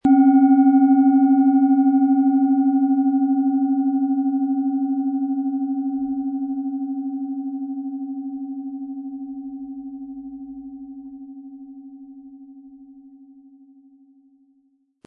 OM Ton
Die Planetenklangschale OM-Ton ist handgefertigt aus Bronze.
Im Audio-Player - Jetzt reinhören hören Sie genau den Original-Klang der angebotenen Schale. Wir haben versucht den Ton so authentisch wie machbar aufzunehmen, damit Sie gut wahrnehmen können, wie die Klangschale klingen wird.
Mitgeliefert wird kostenfrei ein passender Schlegel, der die Schale gut zum Klingen und Schwingen bringt.